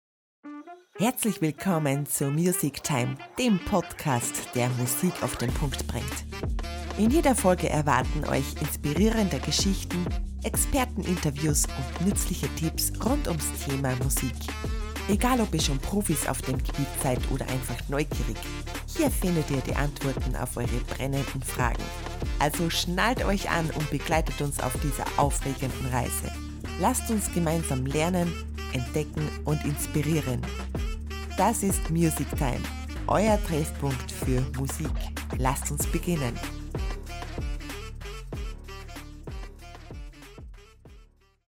Female
Österreichisches Deutsch, warmherzig, freundlich, charmant, beruhigend, energisch
Podcasting
Podcast Intro